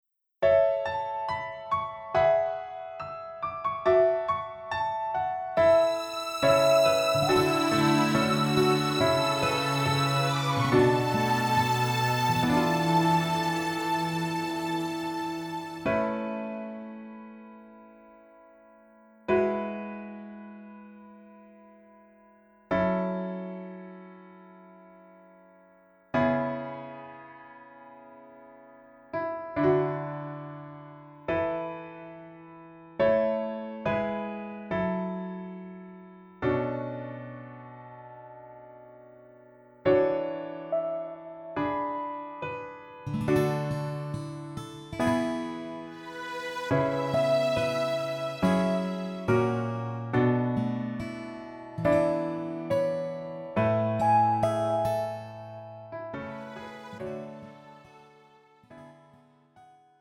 음정 여자키 4:13
장르 가요 구분 Pro MR